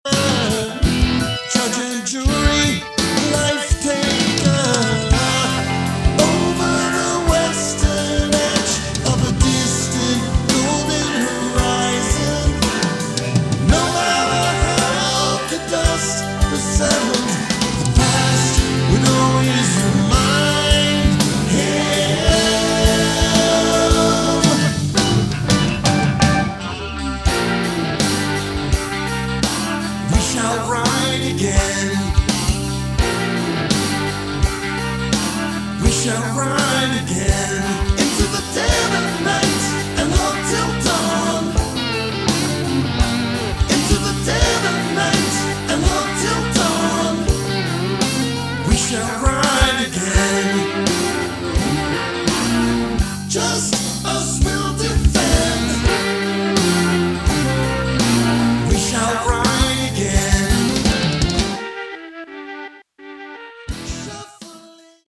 lead and backing vocals, guitars, drums